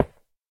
stone2.ogg